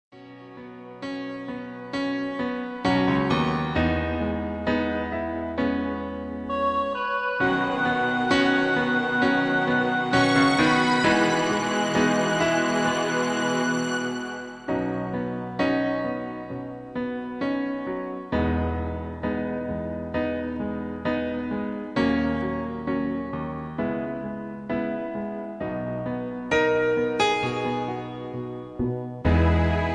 backing tracks
love songs, easy listening